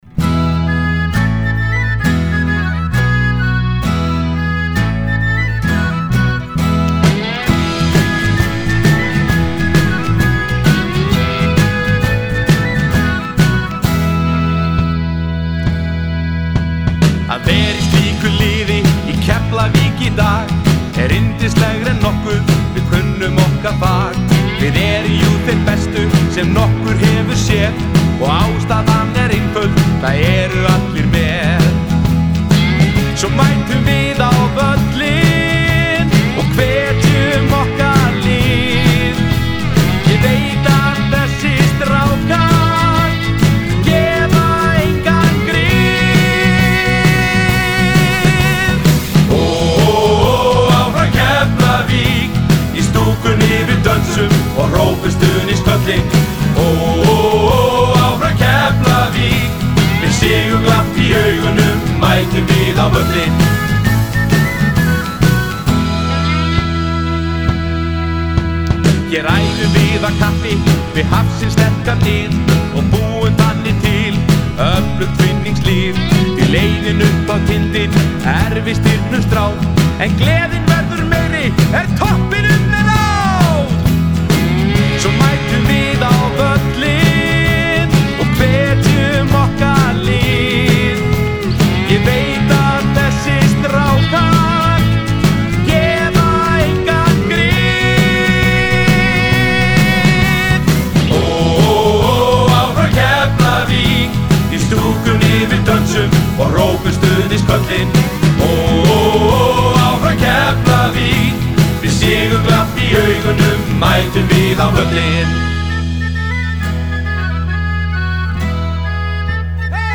söngur og kór.
sólógítar, slide-gítar, kassagítar og kór.
trommur.
bassi.
hljómborð.
flauta.
upptaka og rafmagnsgítar.